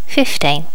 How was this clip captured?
Normalize all wav files to the same volume level.